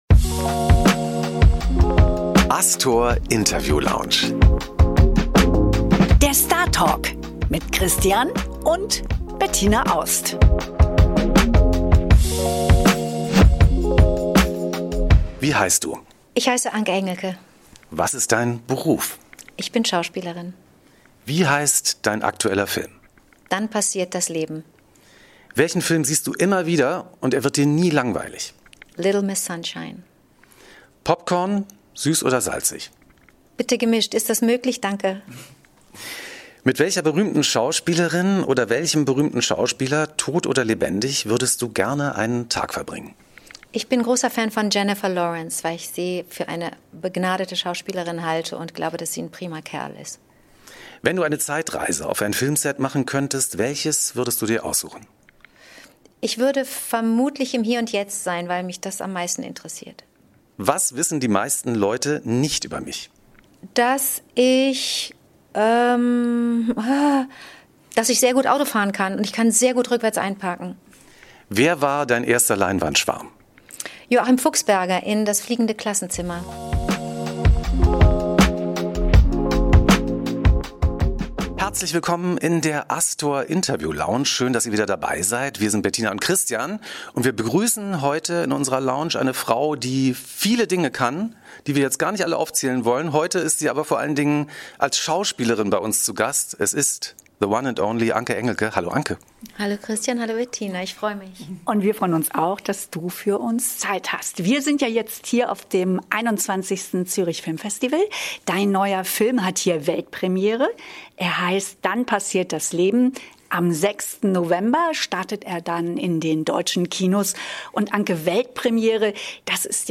Diesmal zu Gast im Podcast: Anke Engelke!